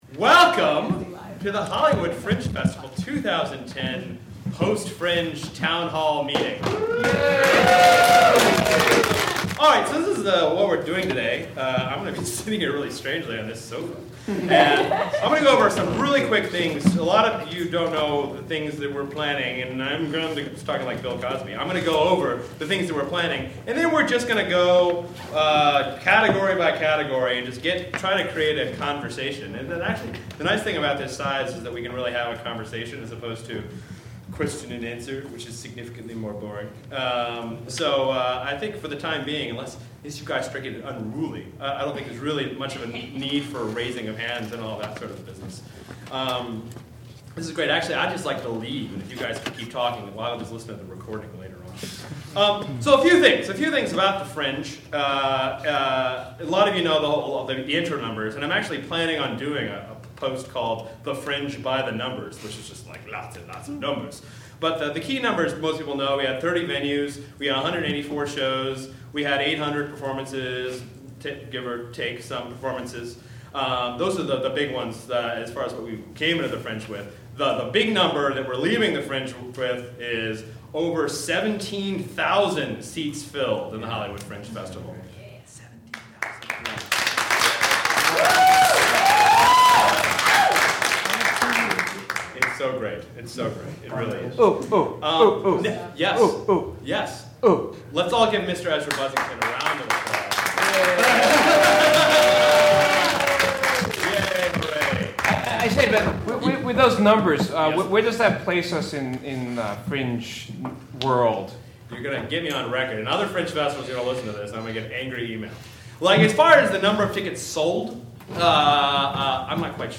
Much was discussed including an enhanced outdoor event, advancements in ticketing and scheduling, new visibility initiatives, and general recaps of Fringe 2010. For those of you who could not attend, we recorded the proceedings for your listening enjoyment…